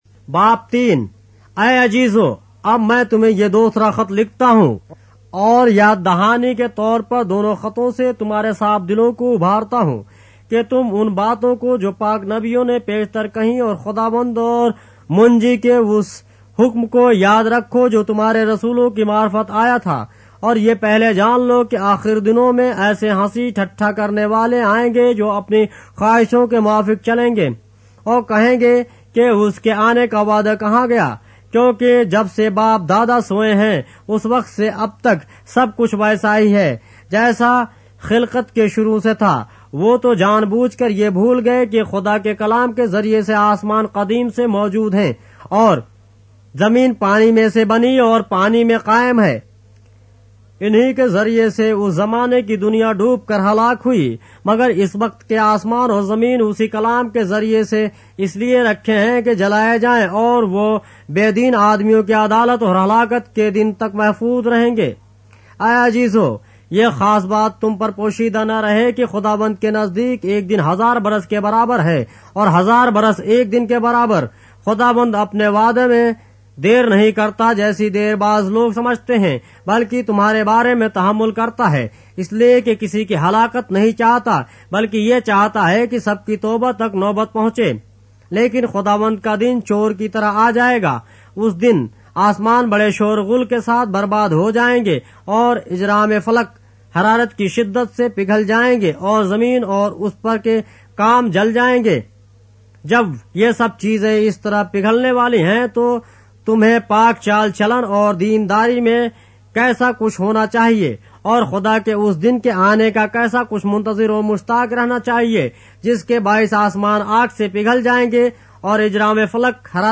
اردو بائبل کے باب - آڈیو روایت کے ساتھ - 2 Peter, chapter 3 of the Holy Bible in Urdu